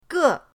ge4.mp3